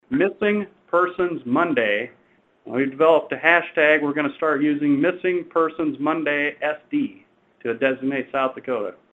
Ravnsborg says the Attorney General’s Office will feature one of the state’s missing residents on social media each week.